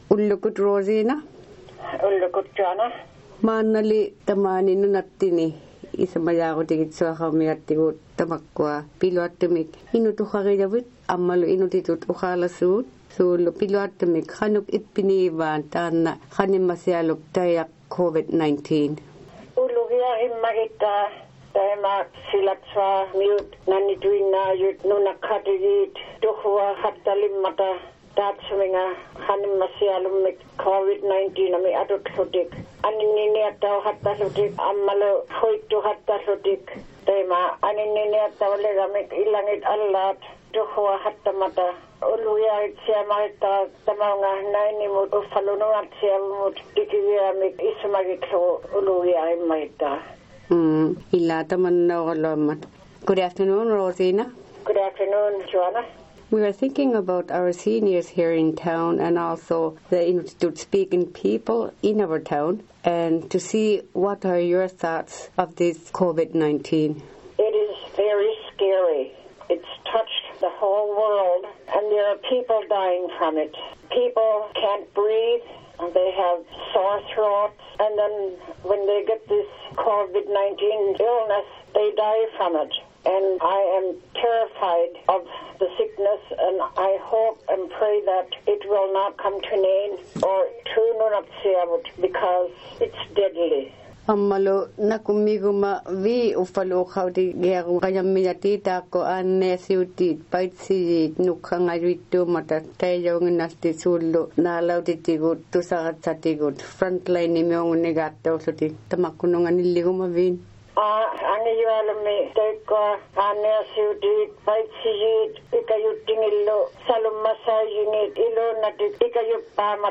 The OK Radio was fortunate to be able to speak with our own two elders of Nain to find out what they would like to say and what their thoughts are about this pandemic.